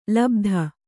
♪ labdha